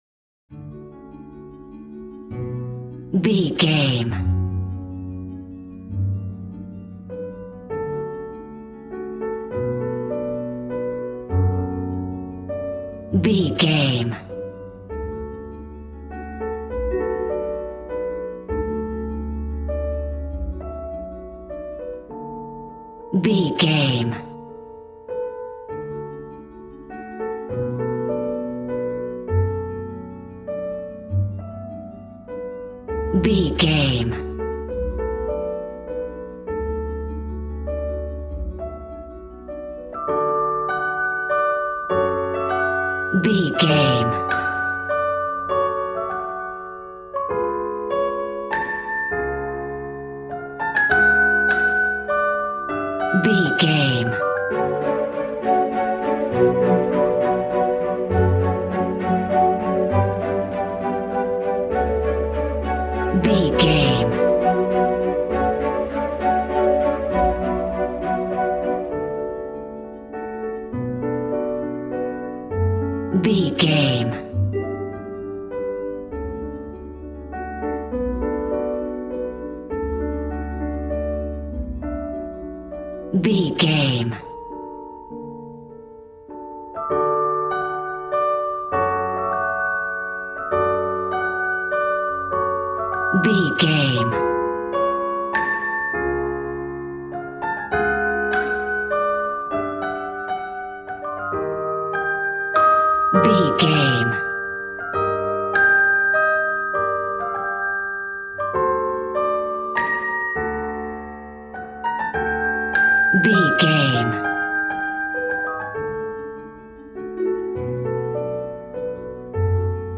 Regal and romantic, a classy piece of classical music.
Ionian/Major
D
regal
strings
violin
brass